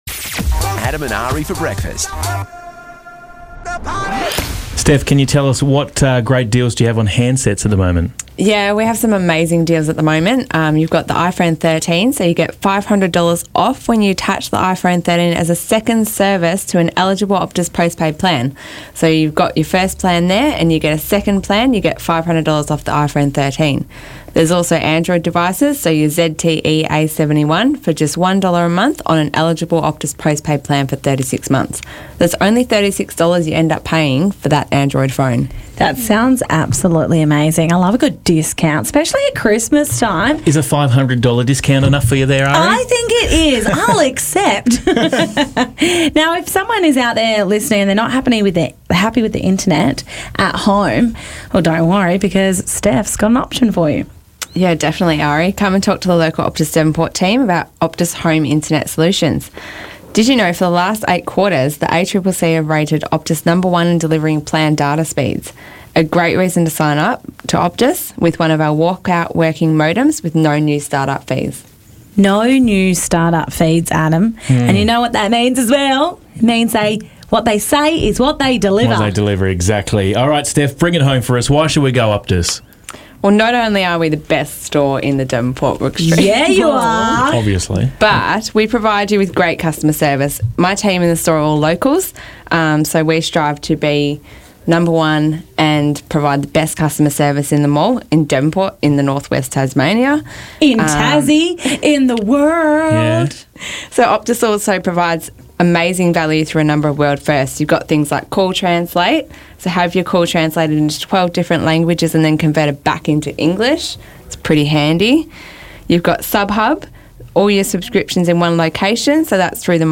CLIP: Interview